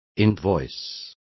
Complete with pronunciation of the translation of invoice.